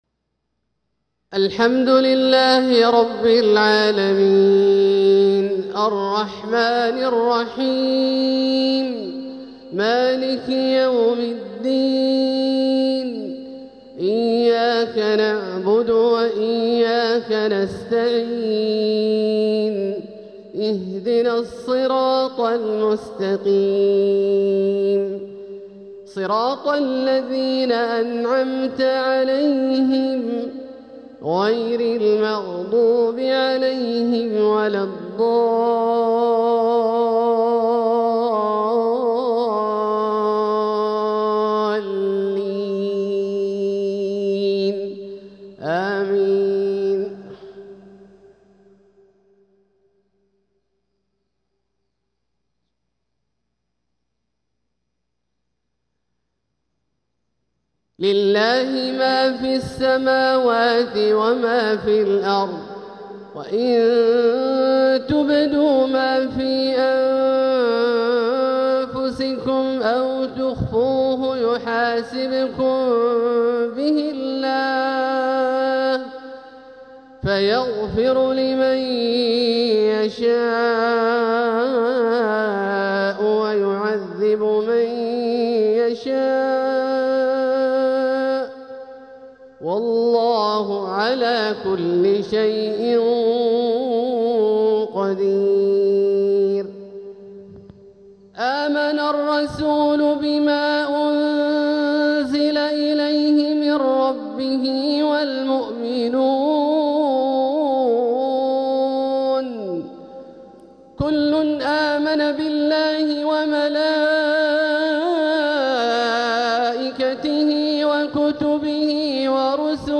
تلاوة لخواتيم سورتي البقرة و الشعراء | مغرب الجمعة 14 صفر 1447هـ > ١٤٤٧هـ > الفروض - تلاوات عبدالله الجهني